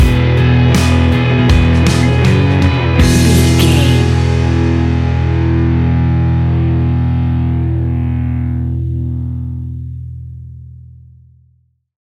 Ionian/Major
indie pop
energetic
uplifting
cheesy
instrumentals
upbeat
groovy
guitars
bass
drums
piano
organ